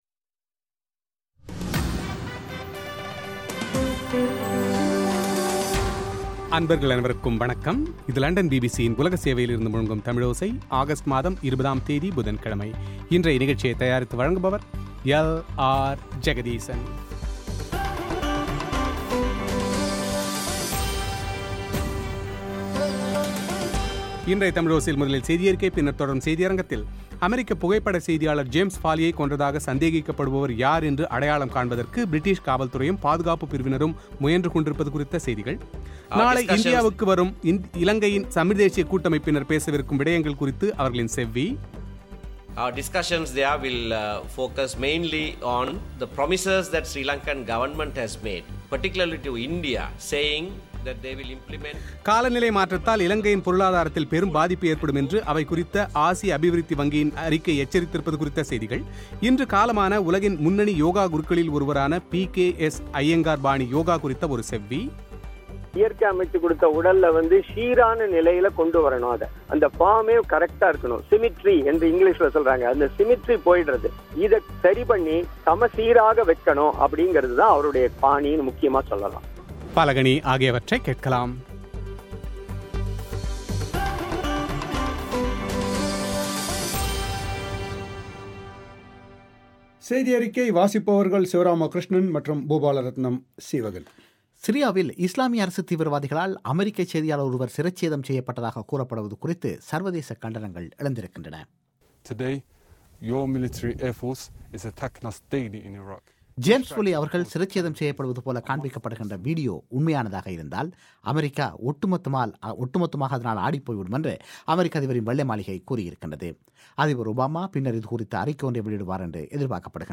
நாளை இந்தியாவுக்கு வரும் இலங்கையின் தமிழ்தேசிய கூட்டமைப்பினர் பேசவிருக்கும் விடயங்கள் குறித்து அவர்களின் செவ்வி;